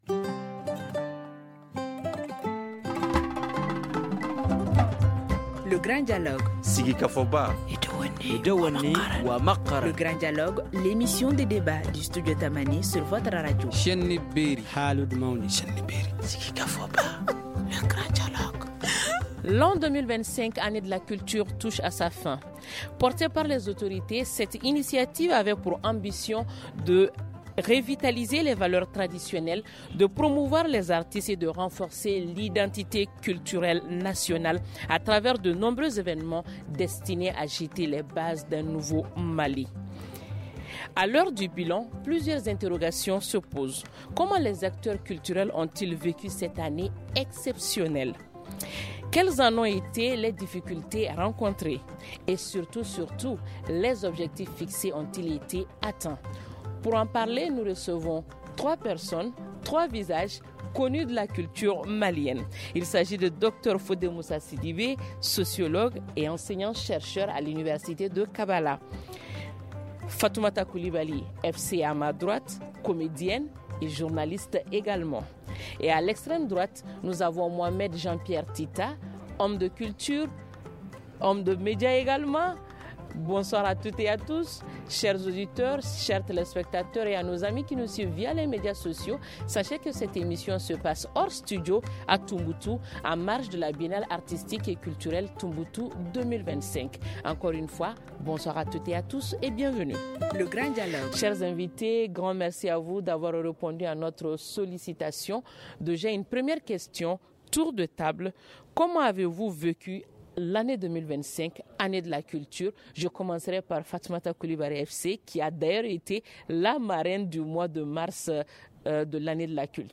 Pour en débattre en marge de la biennale artistique et culturelle Tombouctou 2025, nous recevons ce soir :